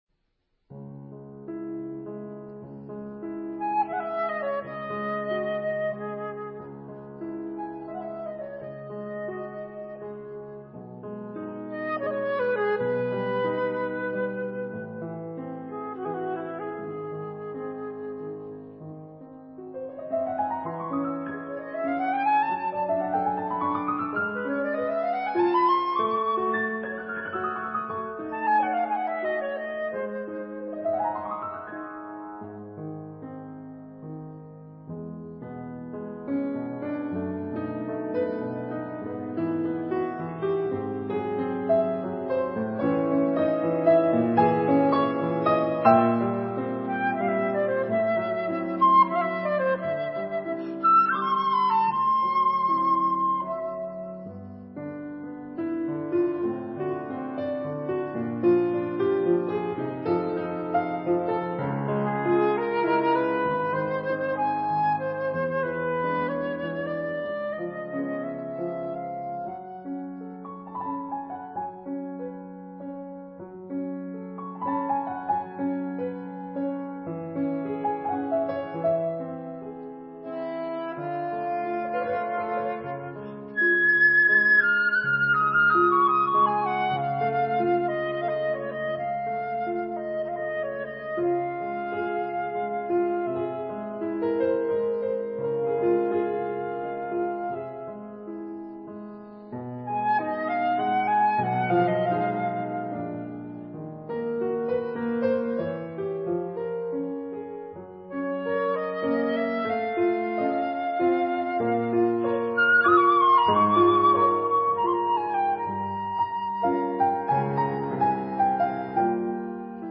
flûte
piano
Dans une atmosphère douce et apaisante, le duo flûte et piano évoque tour à tour le romantisme, le mystère et l’univers du rêve, à travers des œuvres venues d’Italie, de France, du Canada et d’Asie.
I. Andantino con moto